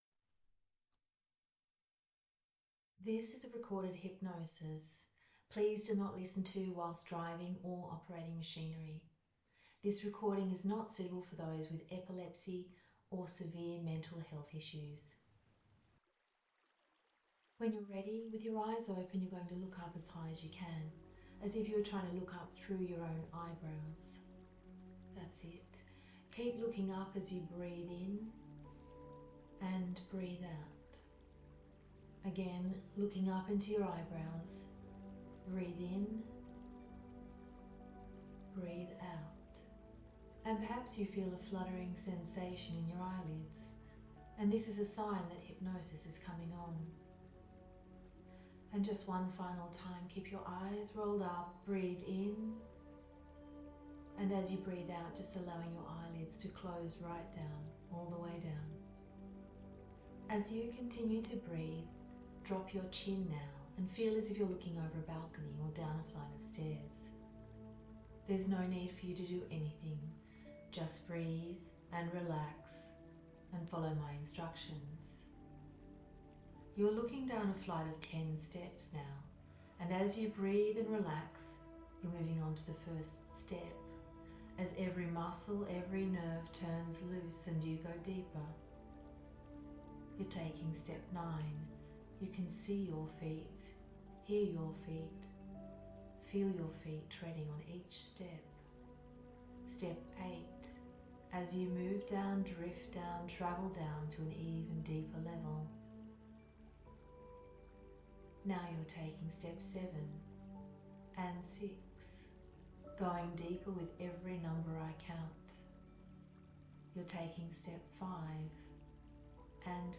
HABITS HYPNOSIS